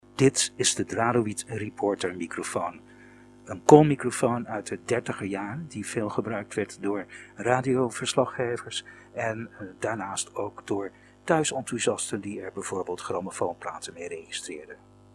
De Dralowid Reporter, een koolmicrofoon uit 1932, was een van de eerste betaalbare semi professionele microfoons.